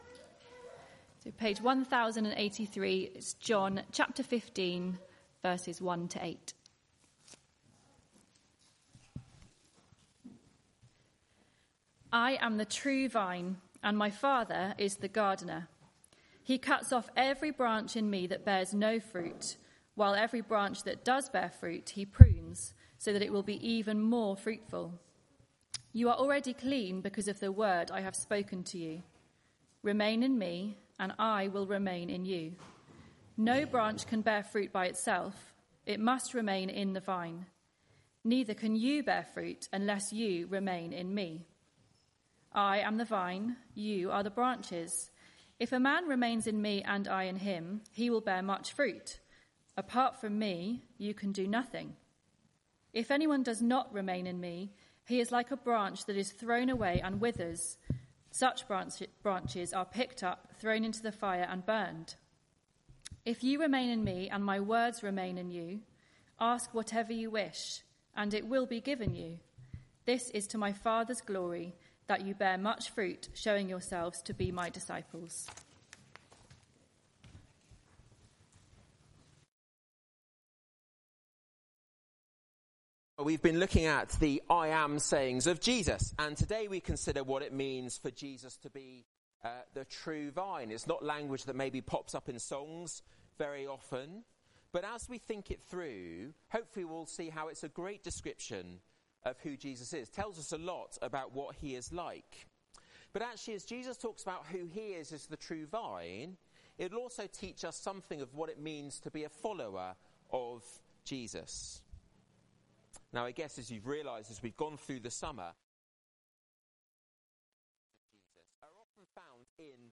Media for Arborfield Morning Service on Sun 01st Sep 2024 10:00
Theme: "I am" sayings of Jesus Sermon